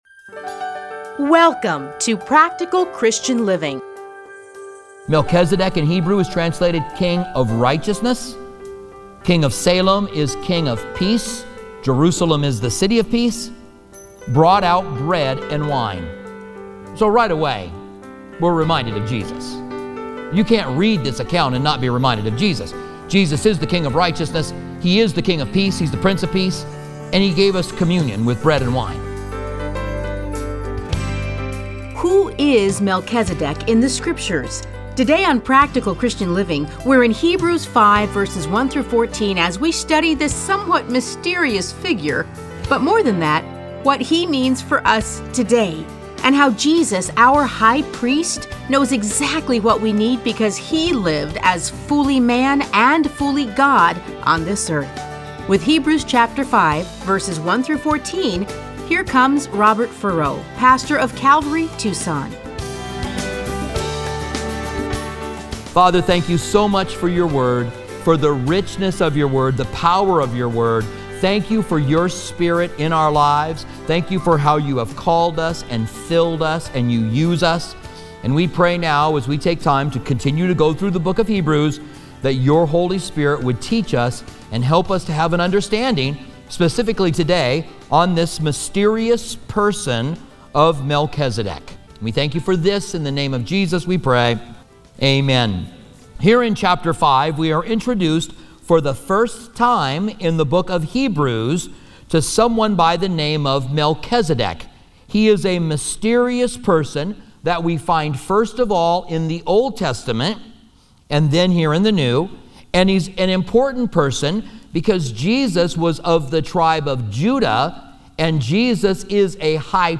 Listen to a teaching from Hebrews 5:1-14.